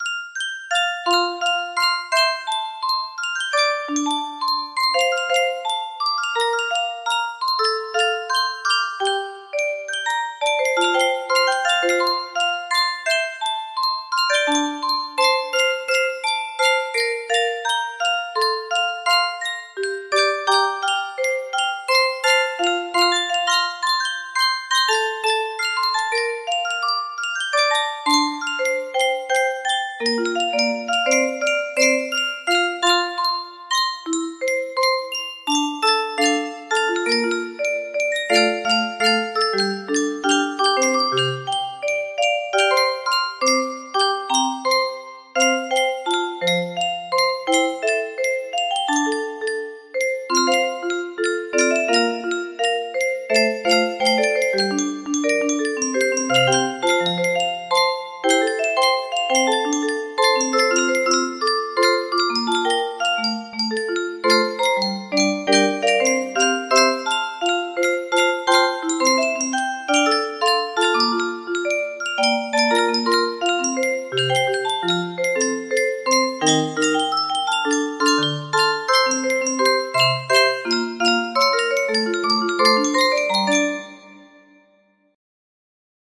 Full range 60
proper tempo, no reds.